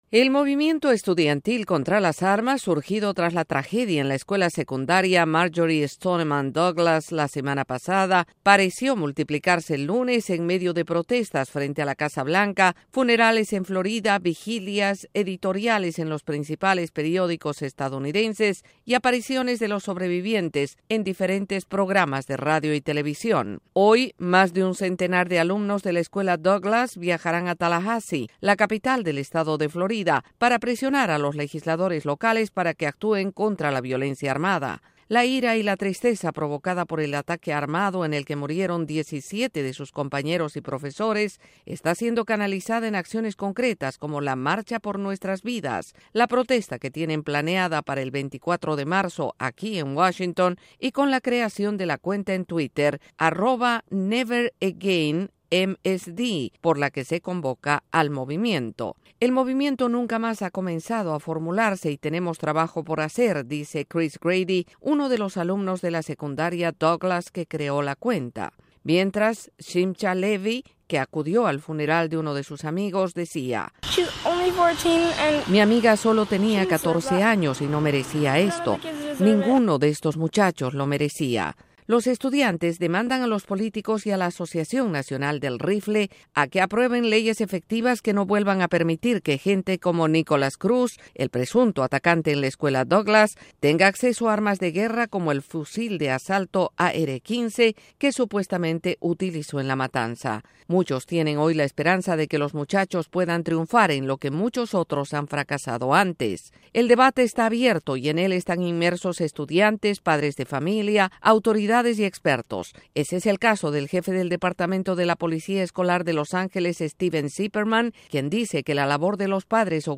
En Florida surge el movimiento estudiantil contra la violencia con armas y en el gobierno y el Congreso se abre, una vez más, el debate sobre el tema. Desde la Voz de América en Washington DC informa